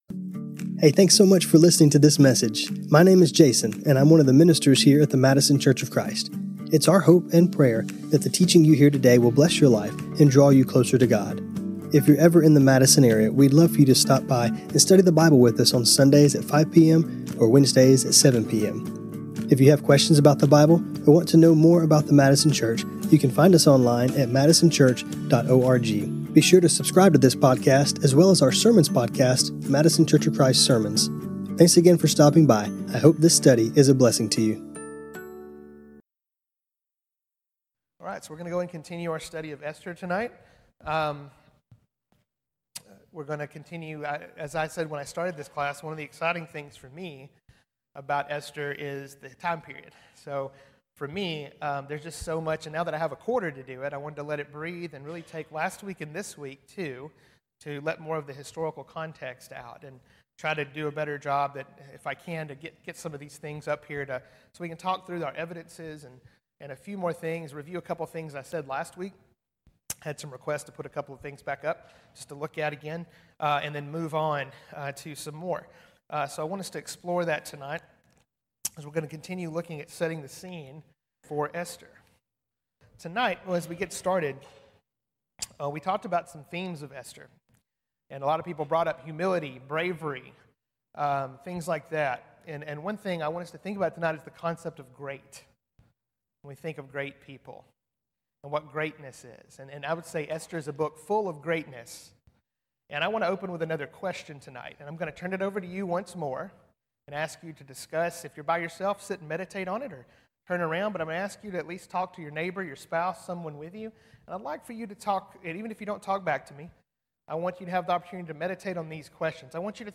This class was recorded on Feb 11, 2026.